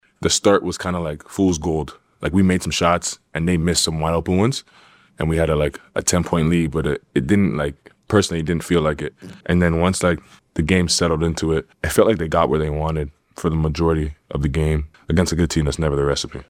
OKC standout Shai Gilgeous-Alexander talks postgame.
SGA on Boston L 3-26.mp3